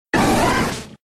Cri de Boustiflor K.O. dans Pokémon X et Y.